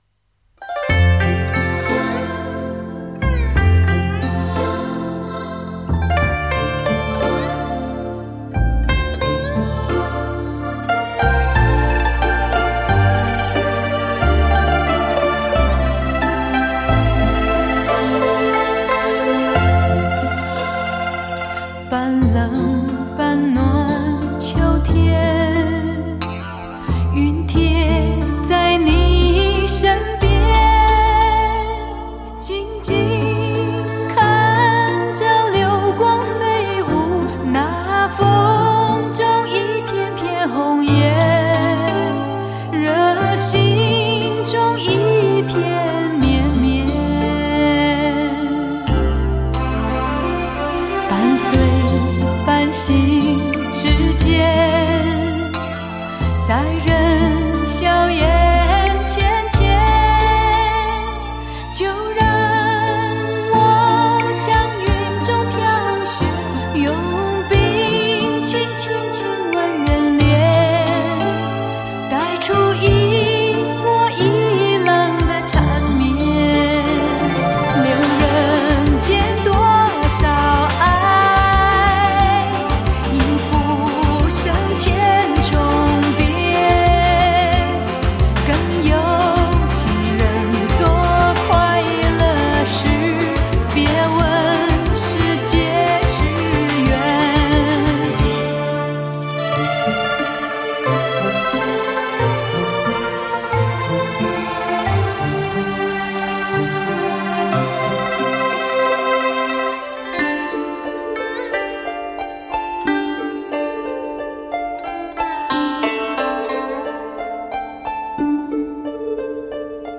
雄伟典范乐曲 必唯天作之合HI-FI典范 极致人声